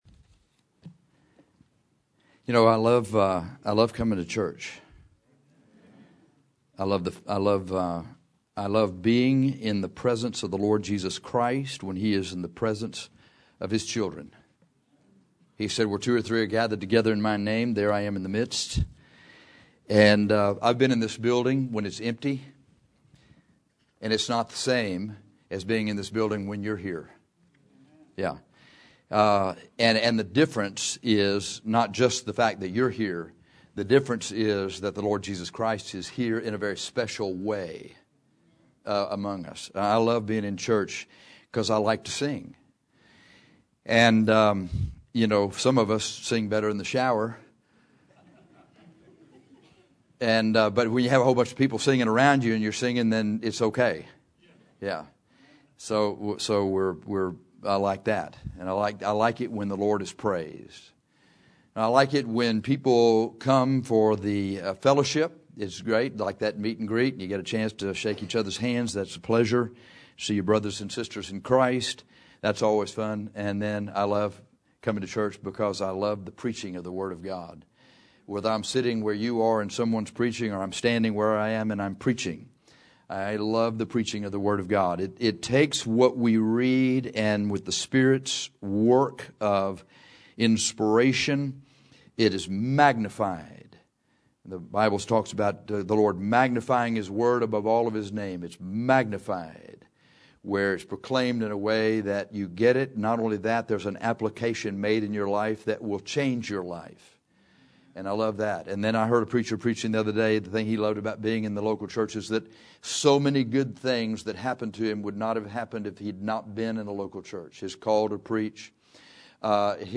In 2 Pet 1:1-10 Peter exhorted us to make your calling and election sure. You do this by four things we discuss in this sermon.